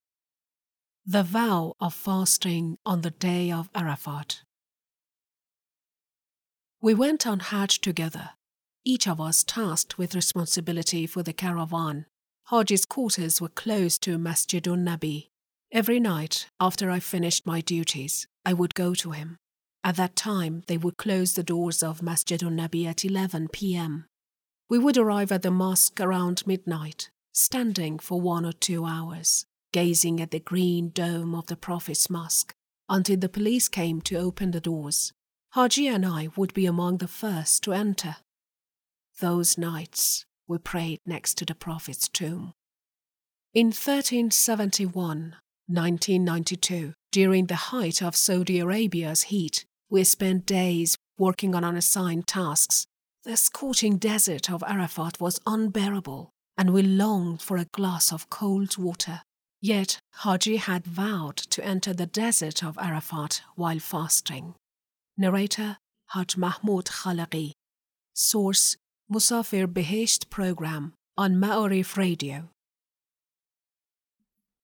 Source: Musafir Behesht program on Maarif Radio